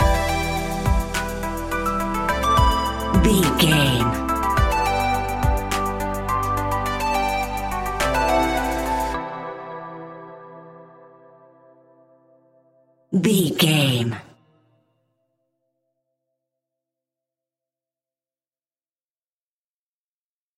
Aeolian/Minor
hip hop
instrumentals
chilled
laid back
groove
hip hop drums
hip hop synths
piano
hip hop pads